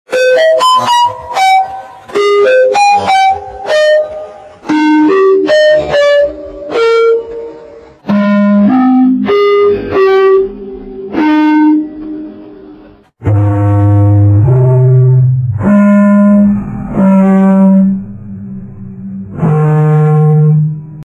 androd lol made with Voicemod technology timestrech 0.74x